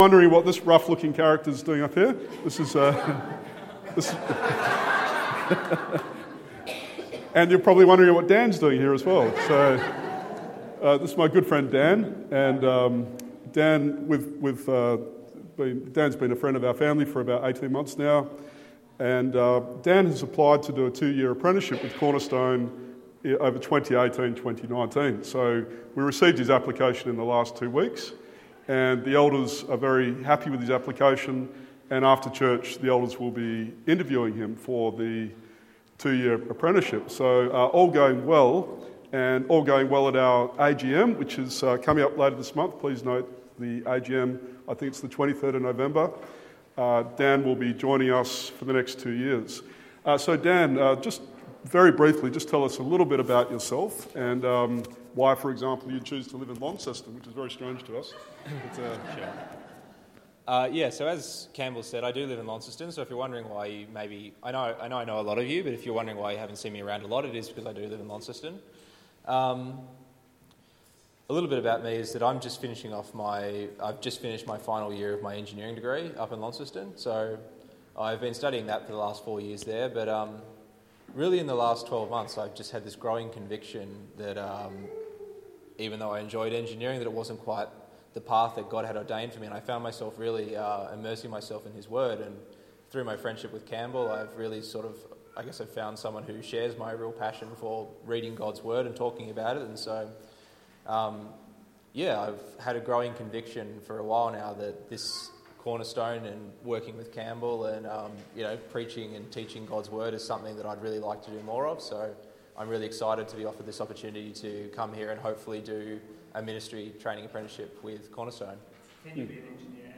Text: Matthew 10:1-42 Sermon